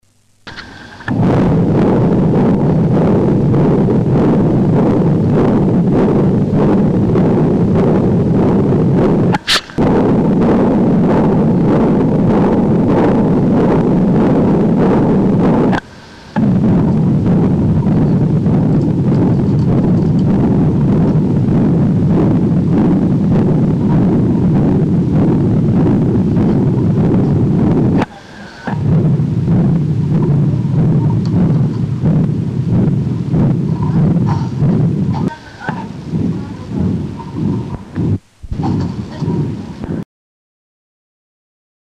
Shuntgeluiden
Hoog frequent OK
Nummer-3-Hoog-frequent-OK.mp3